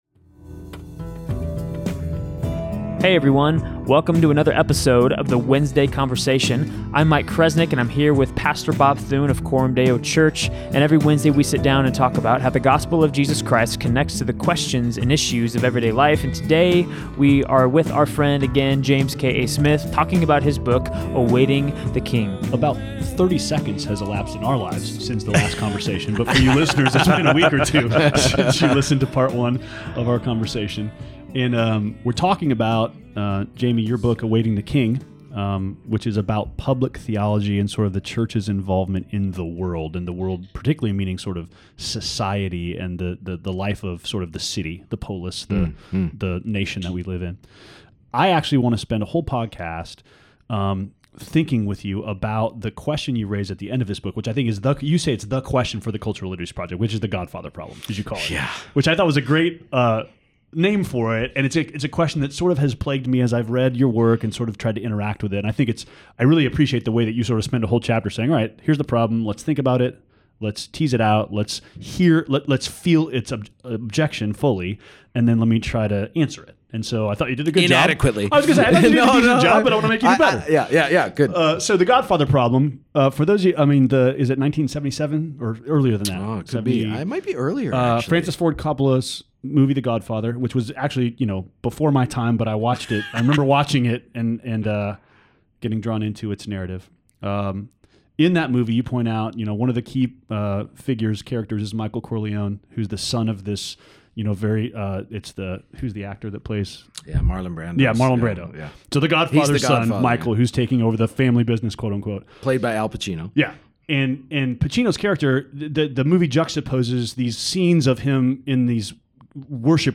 A Conversation with James K.A. Smith | Part 2 — Coram Deo Church Community
We continue our conversation with Dr. James K.A. Smith - an author and philosophy professor at Calvin College. We discuss his book, "Awaiting the King," and the question he raises at the end of the book which he calls, "the Godfather problem."